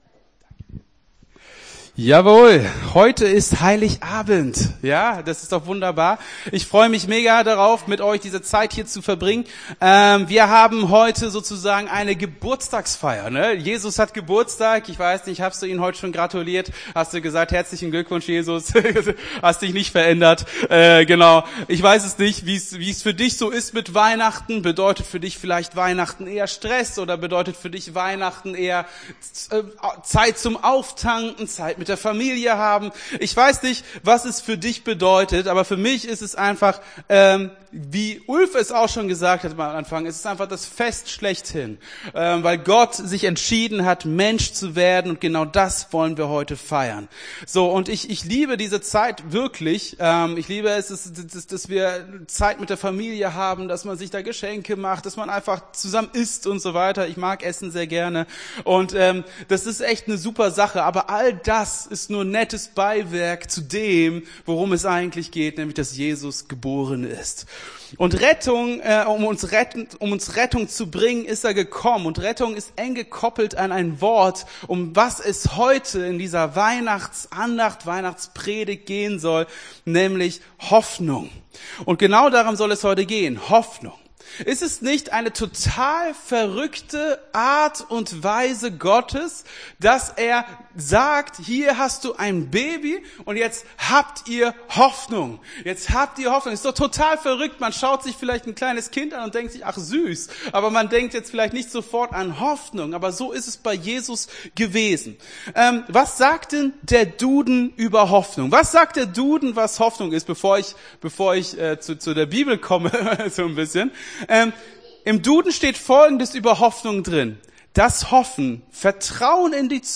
Gottesdienst 24.12.22 - FCG Hagen